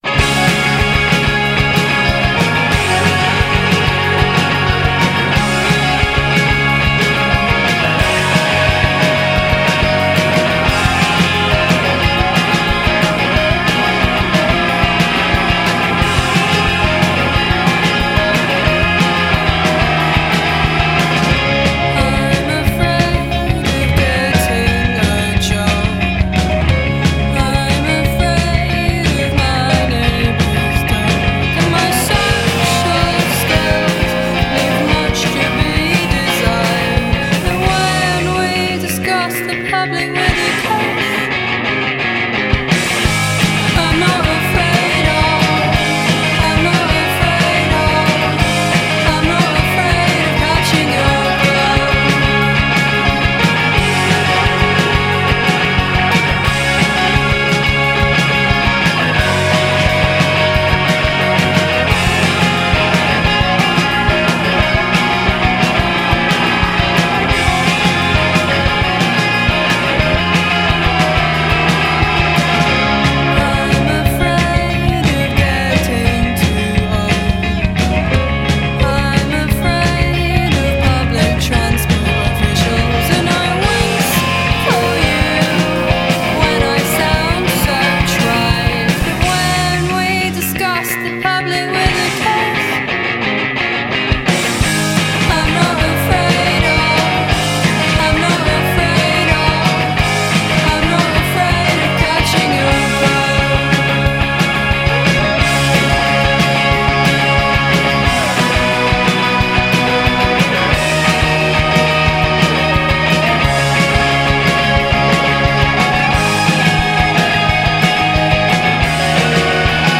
Indiepop tagliente, rabbioso e appassionato: Brit nel cuore.